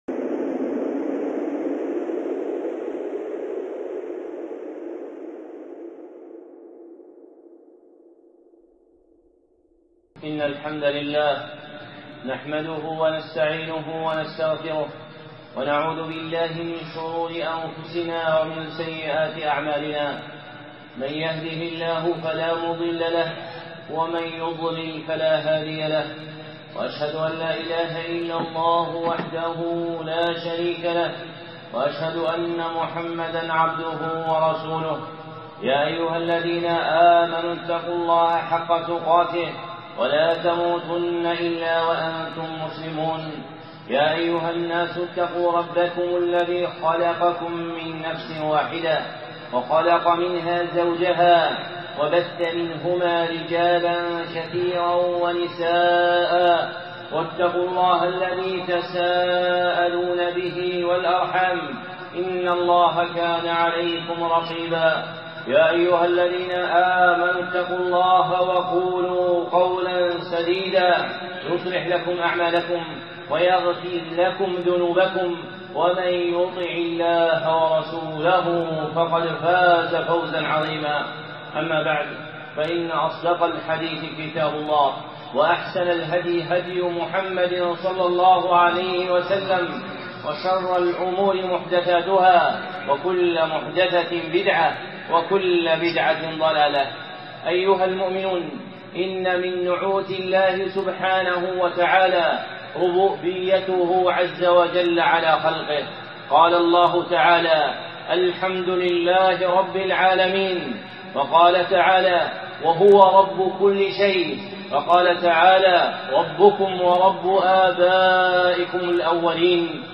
خطبة (مشاهد الربوبية)